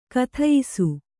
♪ kathayisu